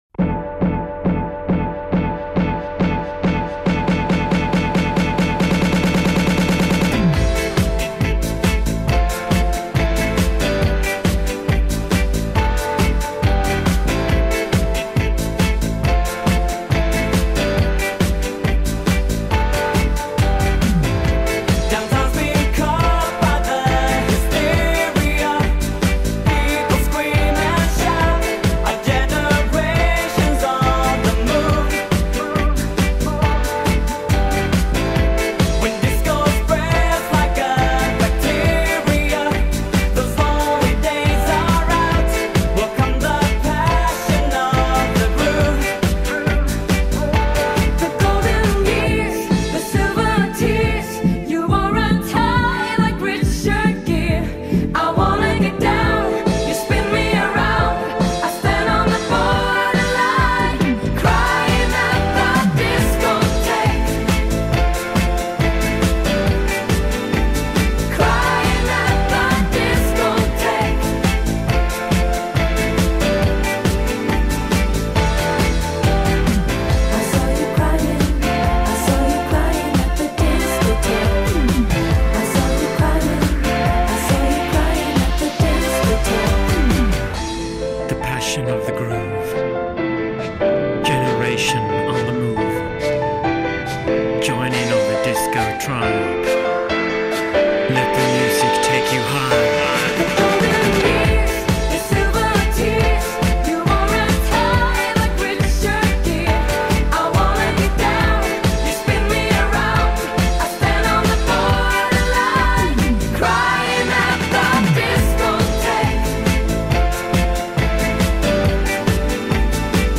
BPM69-138
Audio QualityPerfect (High Quality)
Genre? Gay Pop.